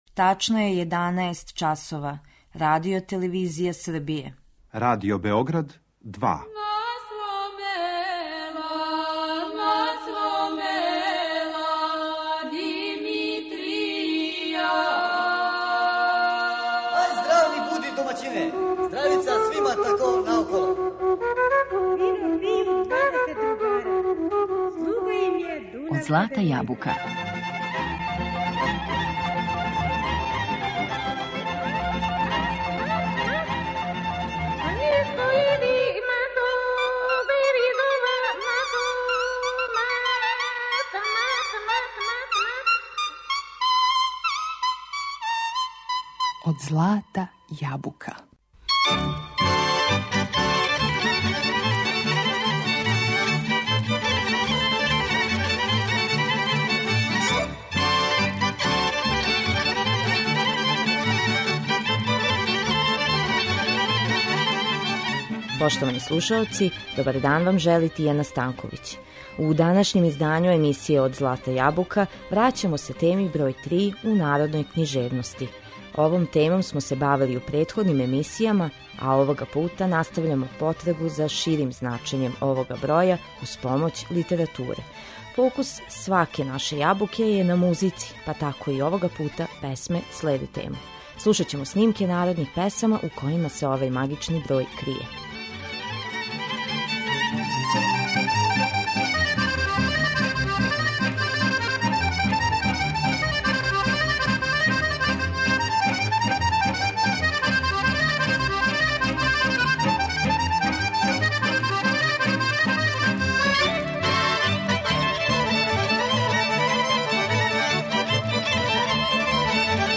Фокус сваке наше Јабуке је на музици па тако и овога пута, песме следе тему. Слушаћемо снимке народних песама у којима се овај магични број крије.